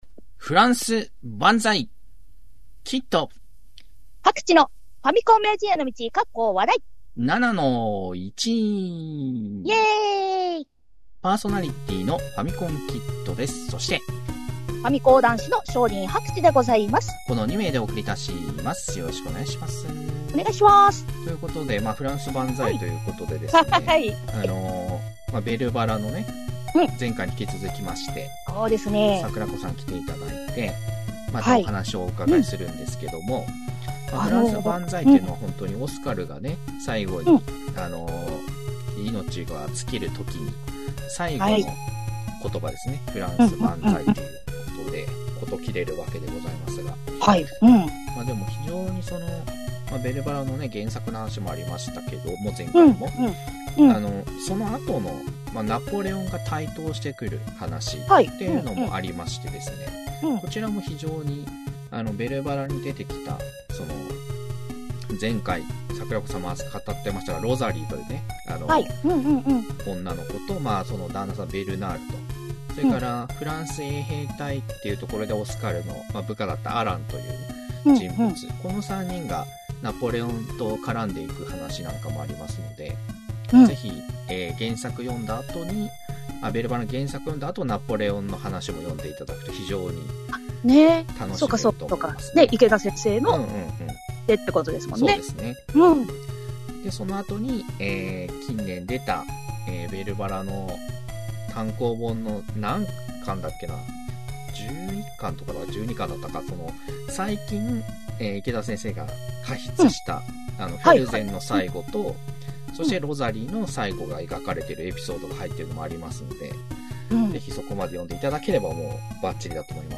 昭和っぽいテイストのインターネットラジオへようこそ！